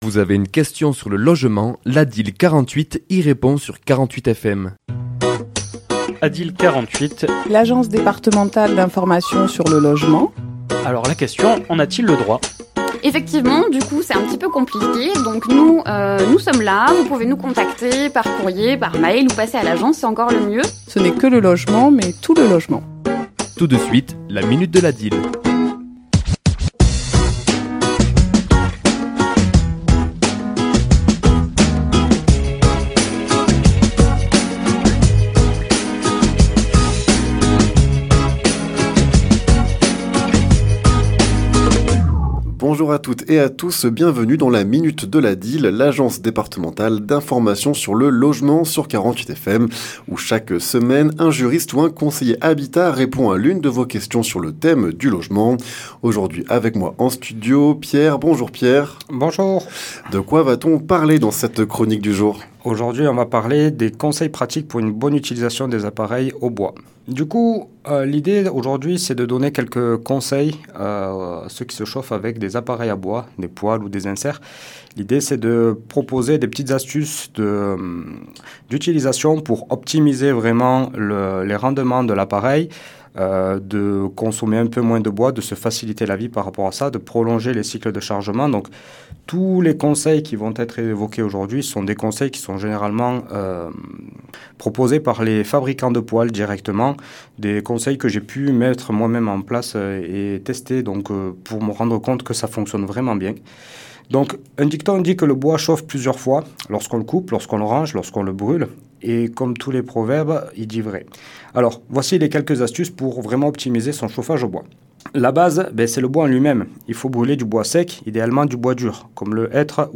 ChroniquesLa minute de l'ADIL
Chronique diffusée le mardi 2 décembre à 11h et 17h10